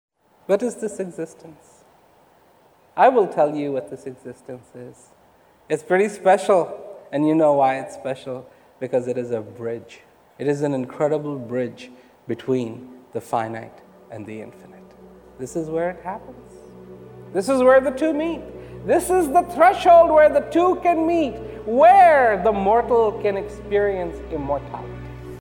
It contains songs and sections of Rawat's speeches sometimes set to music in an attempt to enhance them. Mp3 copies of these excerpts are recorded at high quality (256Kbps) to ensure no nuance or climax is missed.